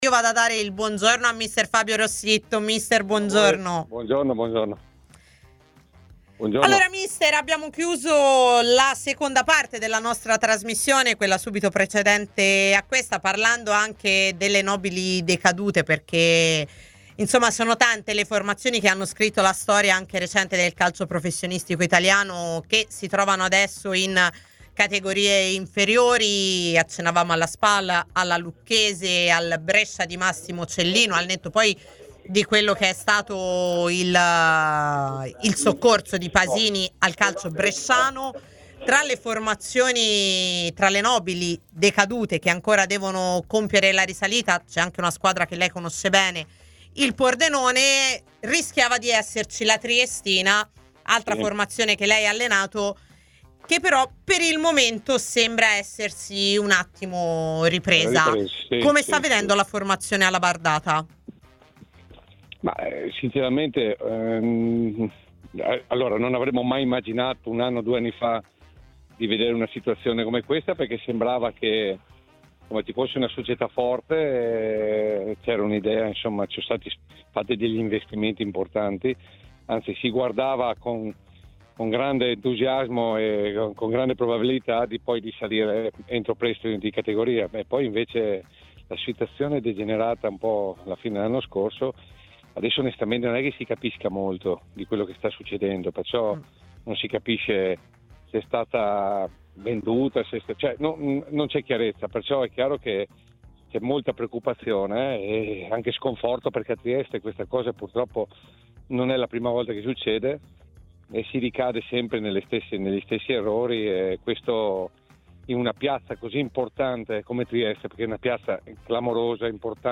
ospite della trasmissione 'A tutta C', in onda su TMW Radio e Il 61